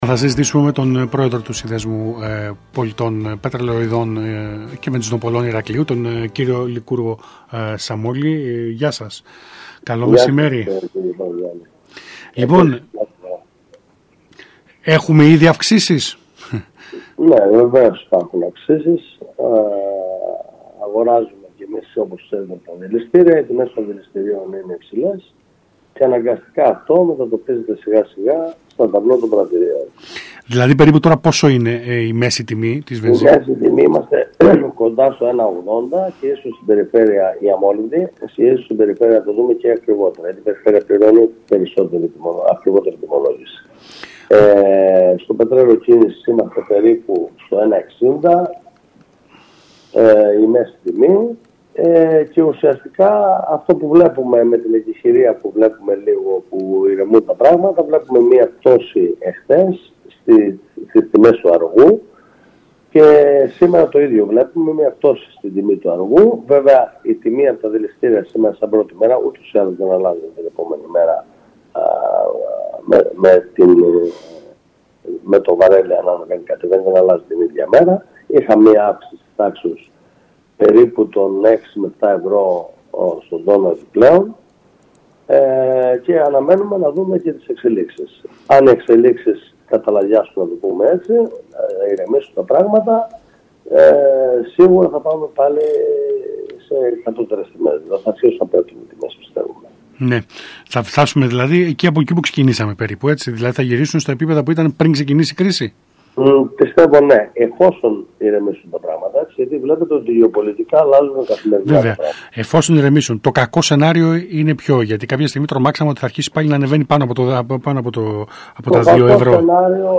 μίλησε το μεσημέρι της Τρίτης 24 Ιουνίου στον ΣΚΑΙ Κρήτης 92.1